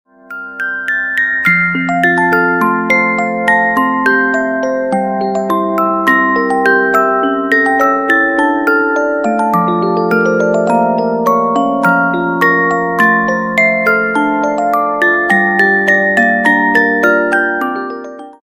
短信铃声
八音盒 特效音效